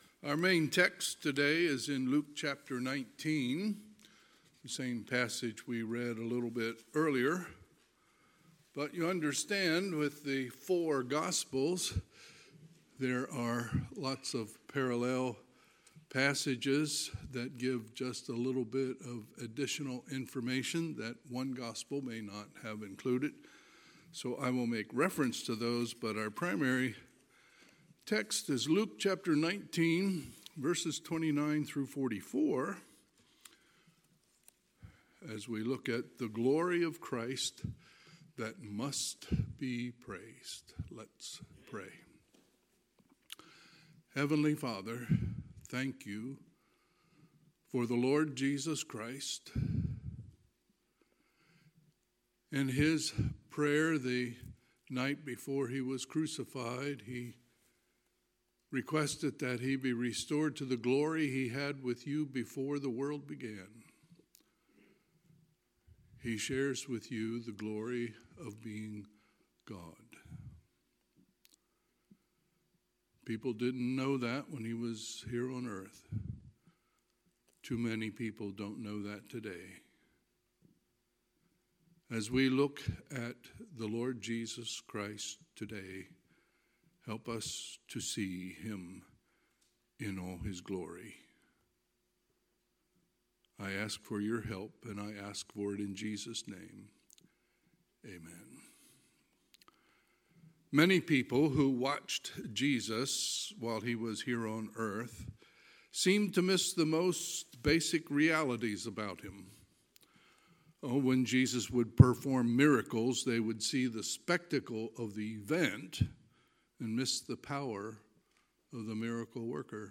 Sunday, March 29, 2026 – Sunday AM